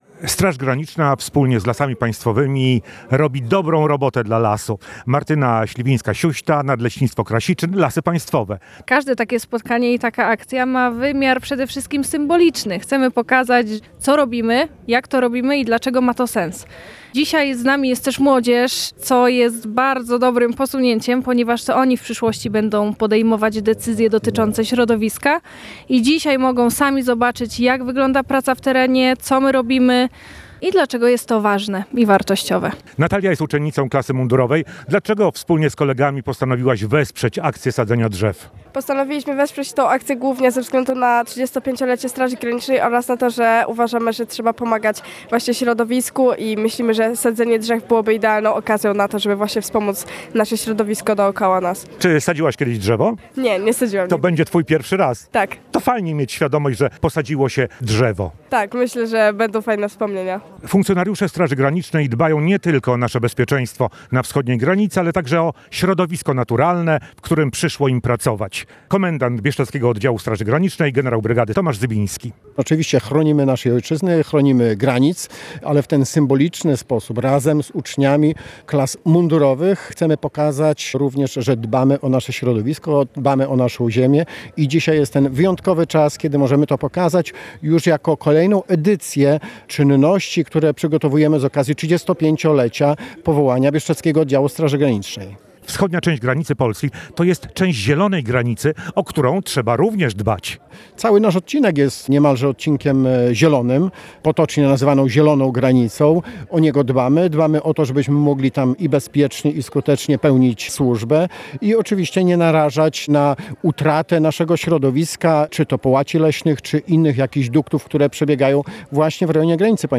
Akcja z okazji Dnia Ziemi • Relacje reporterskie • Polskie Radio Rzeszów